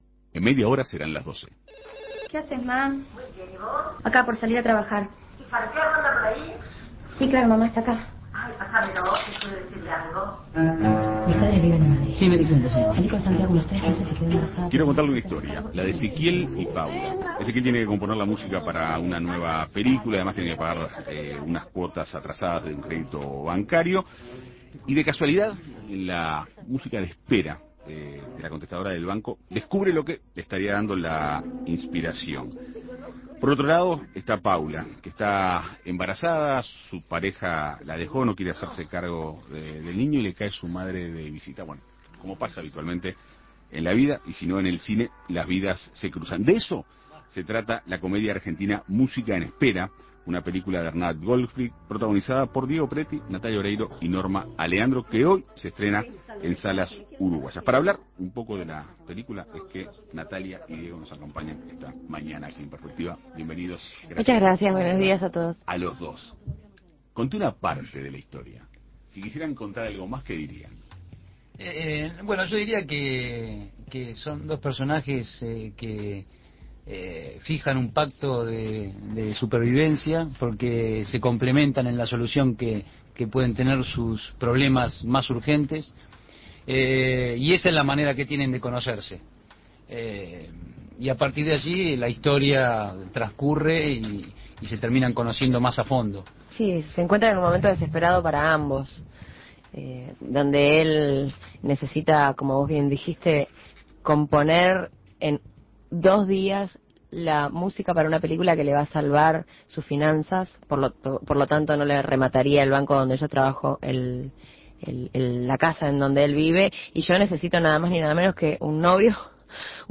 Diego Peretti y Natalia Oreiro fueron entrevistados en Segunda Mañana, en donde hablaron de su nueva película, "Música en Espera", de Hernán Goldfrid, en la que compartieron protagonismo con Norma Aleandro. El film cuenta la historia de Ezequiel, un músico en aprietos económicos, y Paula, quien oculta a su familia que está embarazada y pretende ser madre soltera.